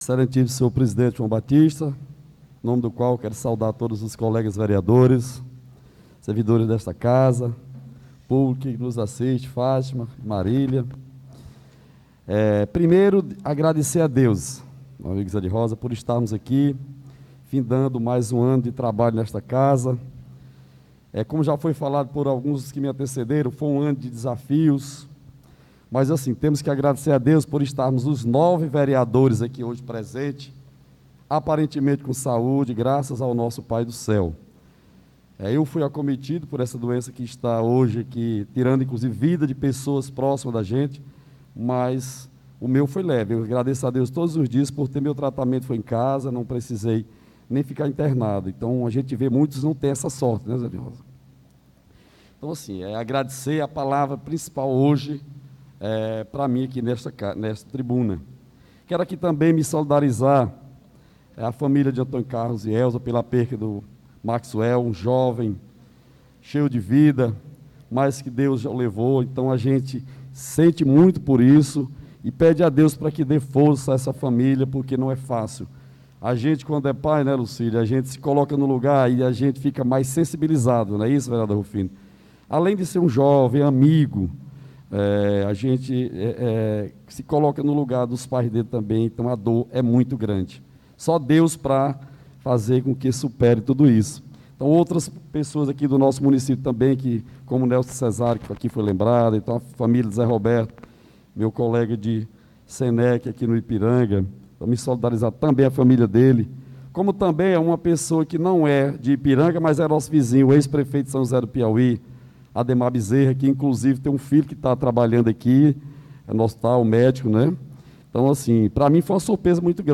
Pronunciamento Ver Edivaldo Fontes
2ª Sessão Ordinária